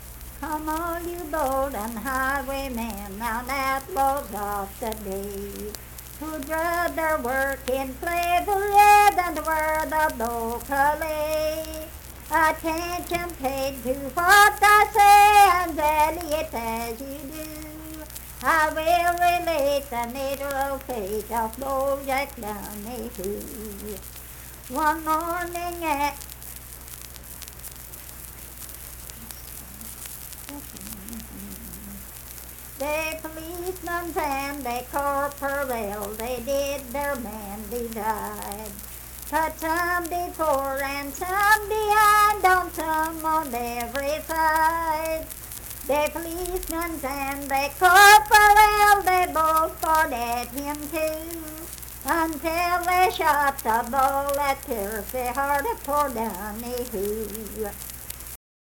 Bold Jack Donahue - West Virginia Folk Music | WVU Libraries
Unaccompanied vocal music performance
Verse-refrain 2d(4).
Voice (sung)